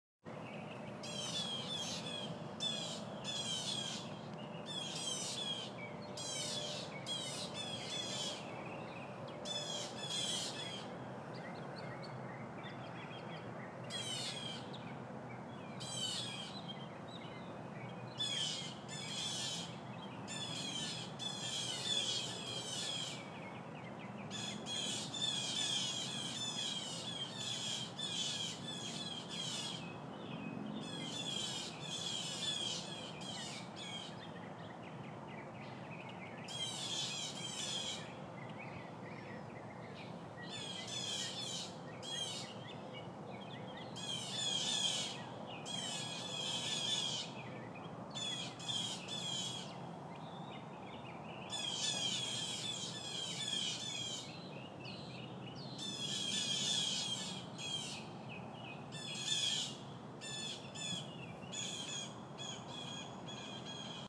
Hawk Alarm
When a hawk appears, the blackbirds go ballistic.
hawk-alarm.m4a